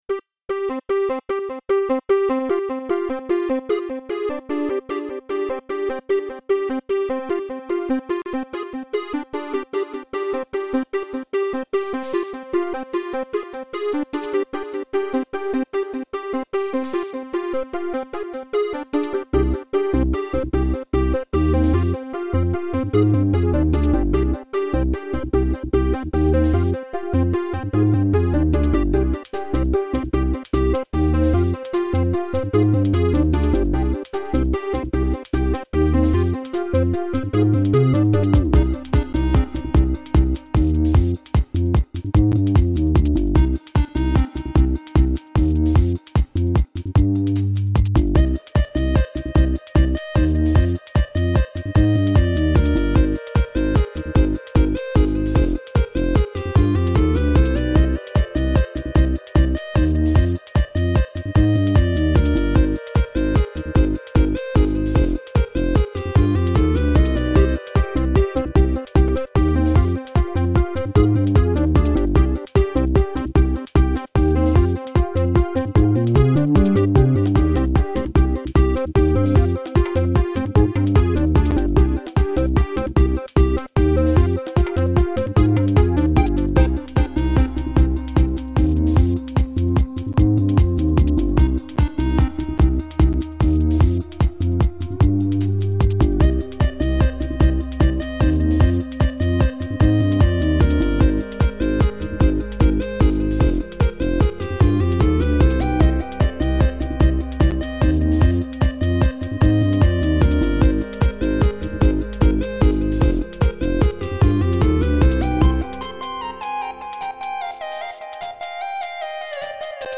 Note that I haven't really bothered making sure that the bounces are great, so there are a few clicks here and there.
:) I love that smooth square sound... Note that this is little more than a draft: it's basically a bunch of ideas cobbled together.